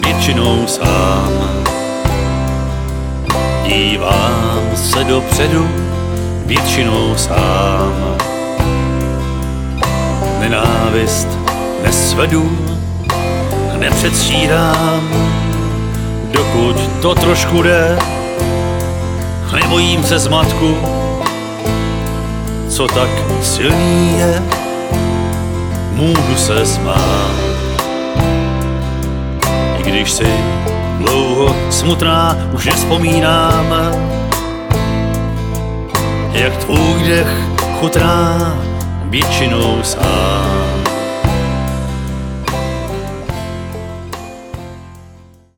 foukací harmonika
klavesy